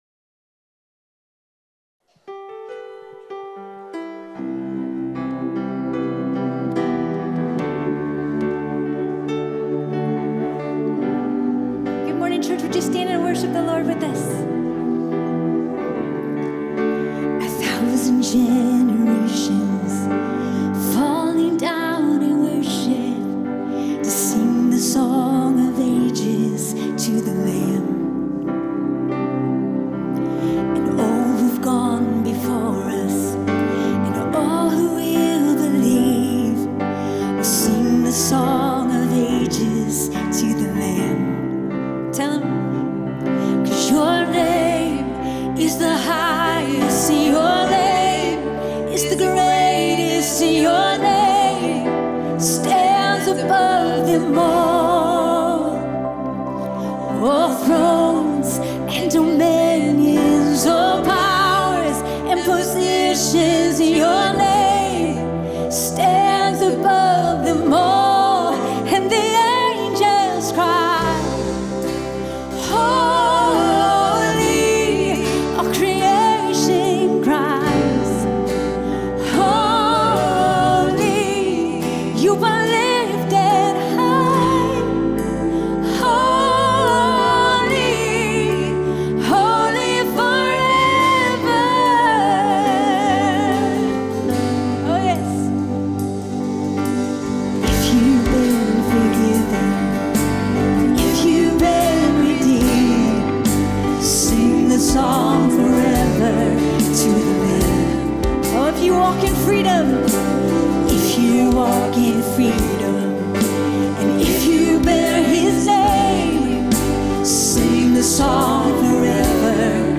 Topic: Show on Home Page, Sunday Sermons